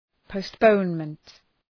Προφορά
{pəʋst’pəʋnmənt}